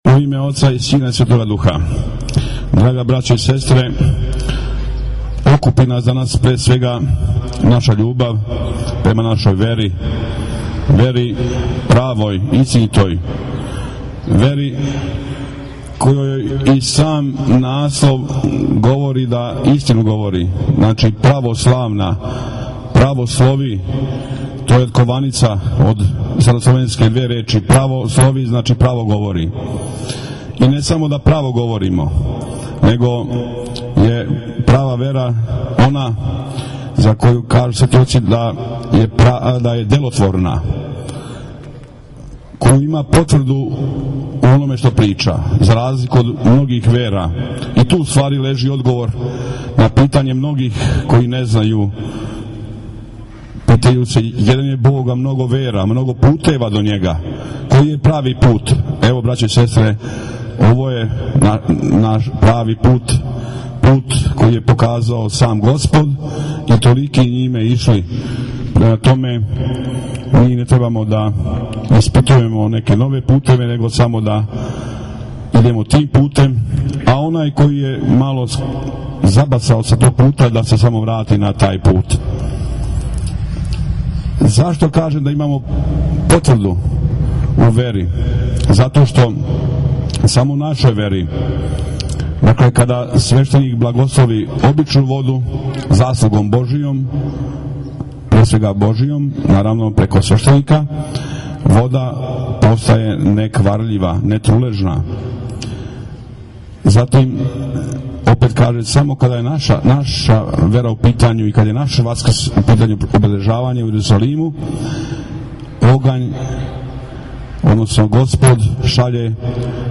Беседе